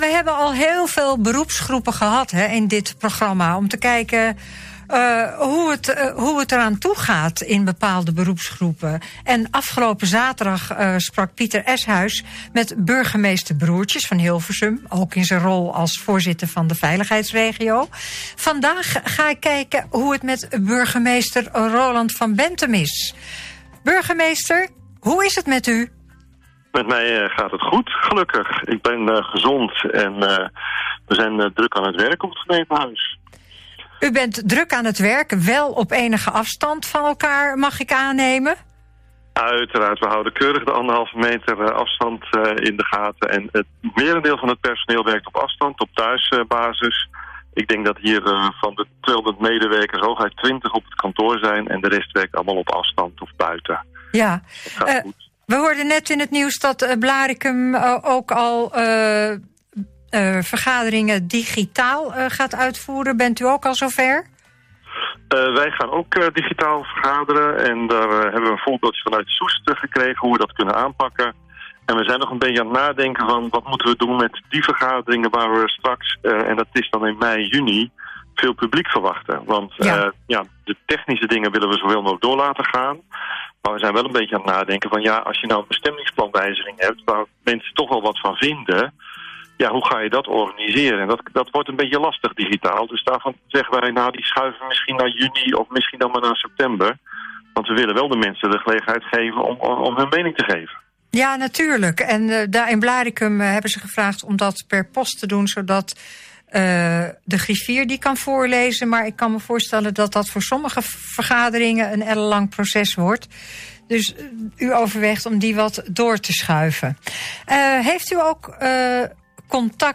Interview met Burgemeester Roland van Benthem van Eemnes over de invloed van de corona epidemie op zijn gemeente.